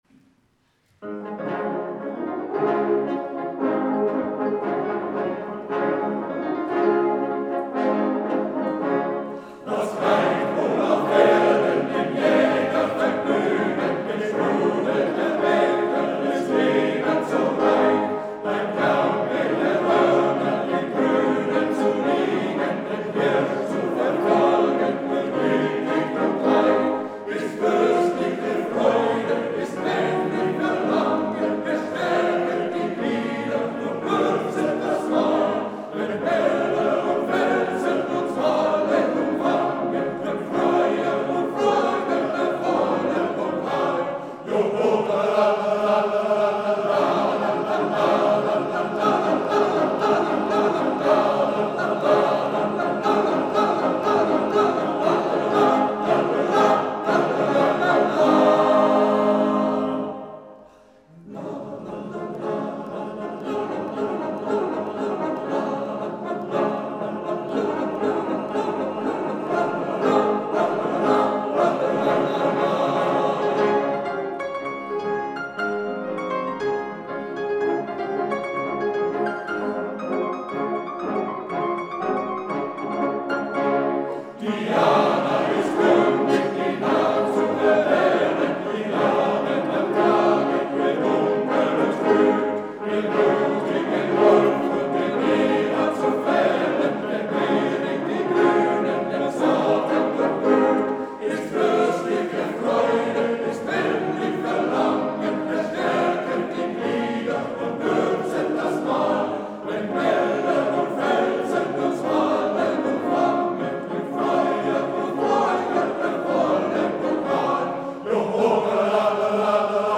Sängerkreiskonzert 15. Juli 2023